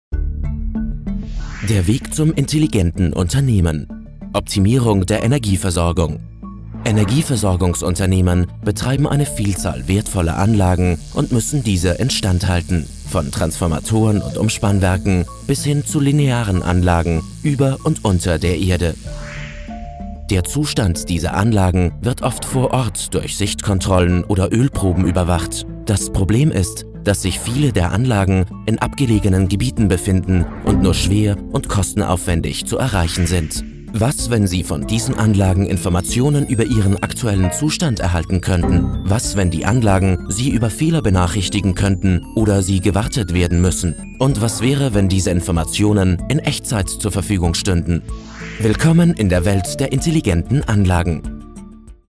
Klangfarben: ruhig, smart, kräftig, dynamisch aber auch gerne schrill, kratzig und frech.
Sprechprobe: Industrie (Muttersprache):
Imagefilm Energieversorgung.mp3